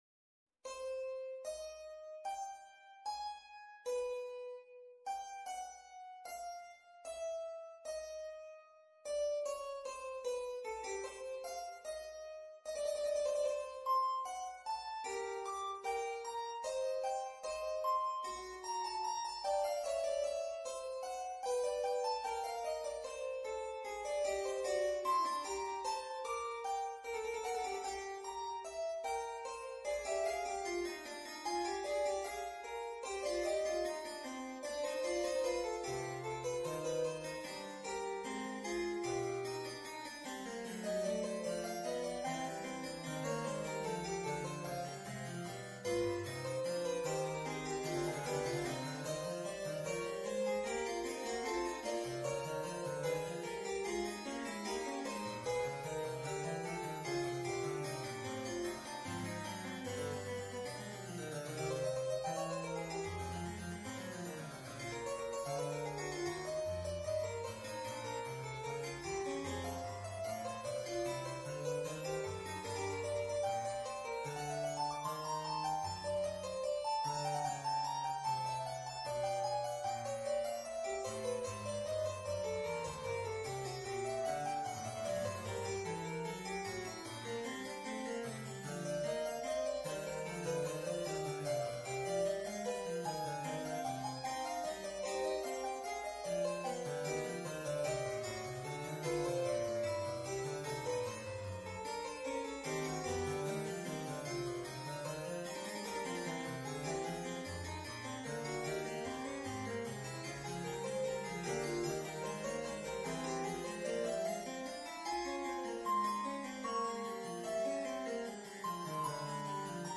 Harpsichord version
Instrument: Harpsichord
Style: Classical